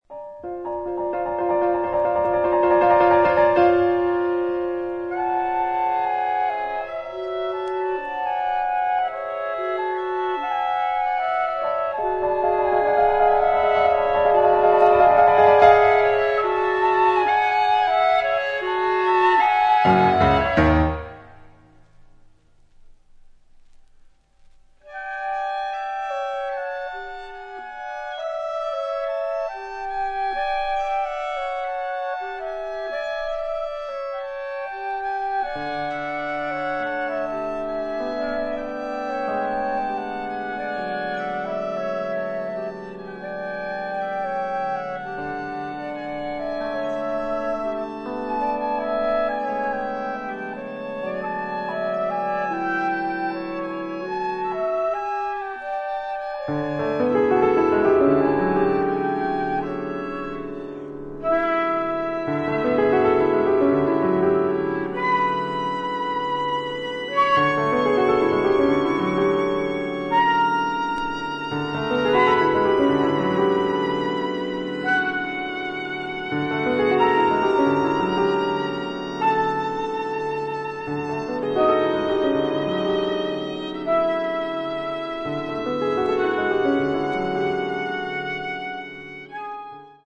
A2c Tranquillo Ma Animato